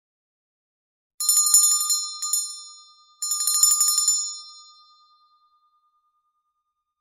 Vánoční zvoneček ke stažení
Klasický zvoneček
KLASICKY-ZVONECEK.mp3